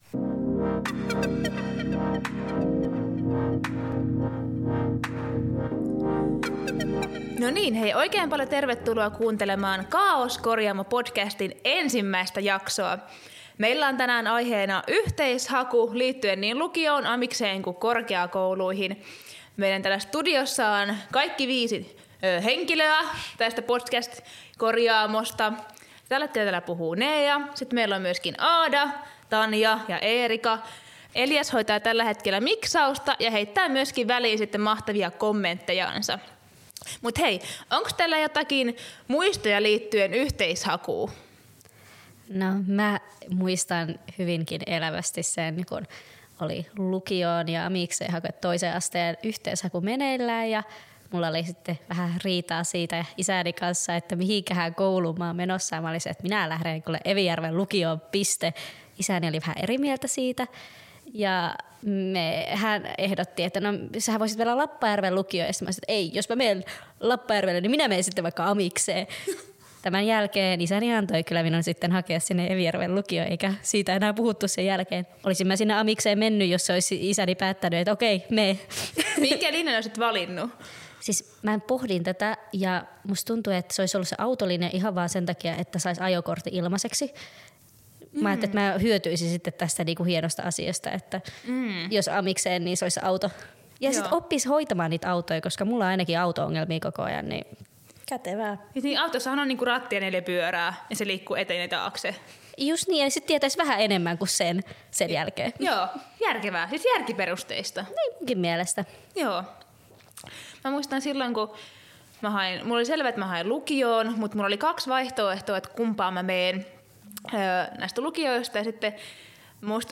Kaaoskorjaamo on Kortesjärven Ylikylän Nuorisoseuran ja Kauhavan Nuorisovaltuuston yhteinen podcast, jossa kuuluu nuorten oma ääni.– Lyt til Kaaoskorjaamo øjeblikkeligt på din tablet, telefon eller browser - download ikke nødvendigt.